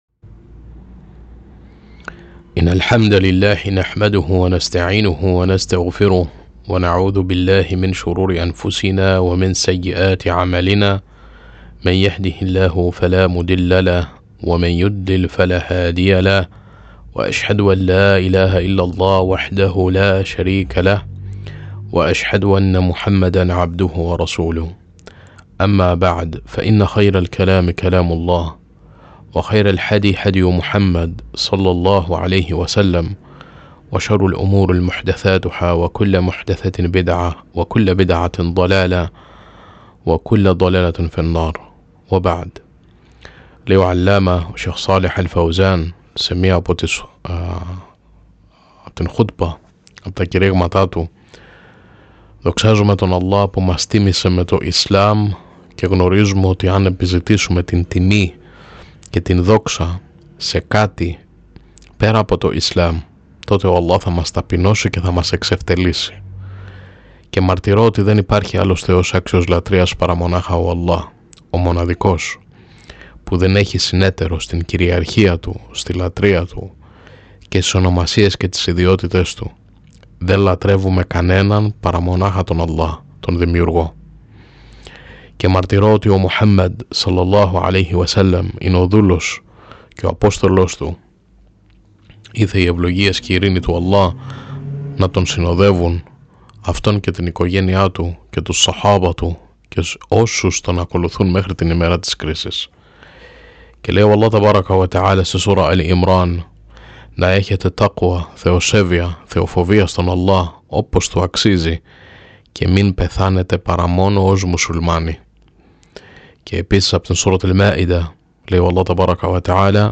“Κράτα γερά στο Ισλάμ”: Απόσπασμα από την Χούτμπα της Παρασκευής του Σέηχ Σάλιχ αλ-Φαουζάν – التمسك بدين الإسلام